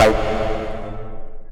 tekTTE63009acid-A.wav